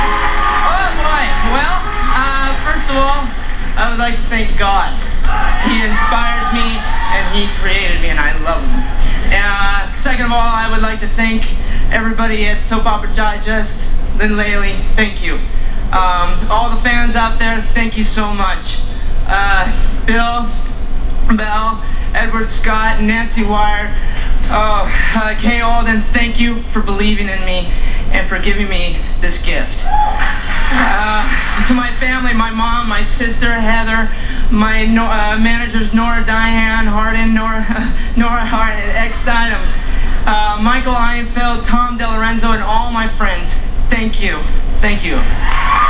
David's Acceptance Speech: